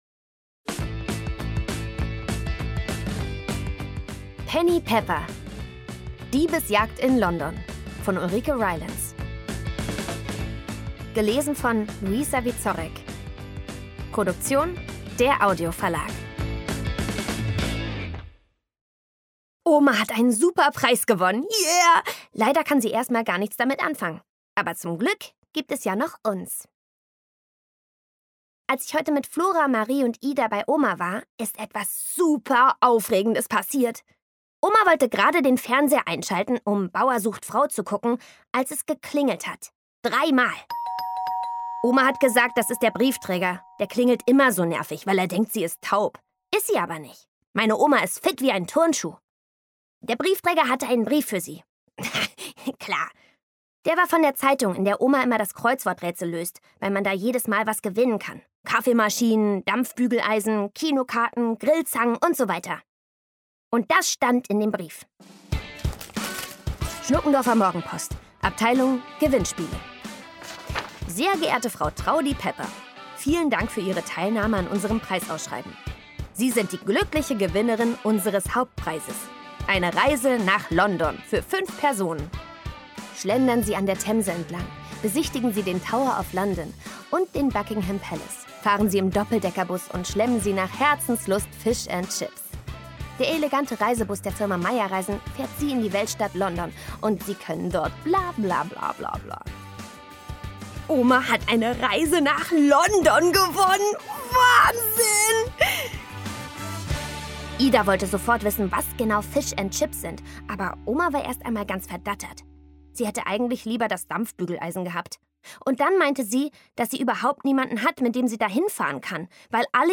Penny Pepper – Teil 7: Diebesjagd in London Szenische Lesung mit Musik